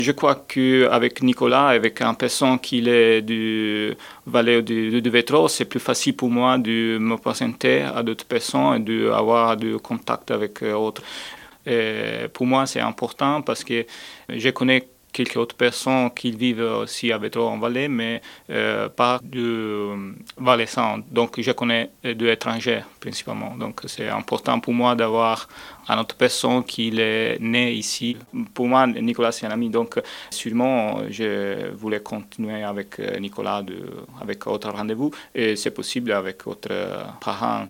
L'info à chaud de Rhône FM du 30 septembre 2016 : Interview